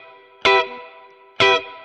DD_StratChop_130-Emin.wav